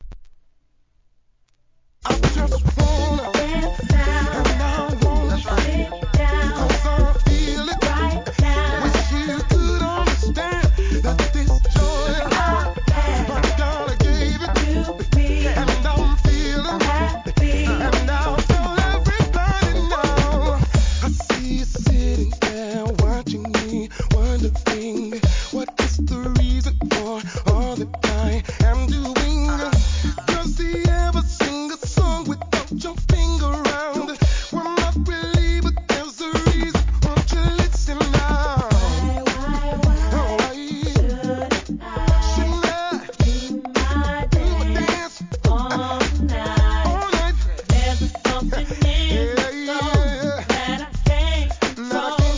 HIP HOP/R&B
タイトル通りのUP TEMPO R&B!!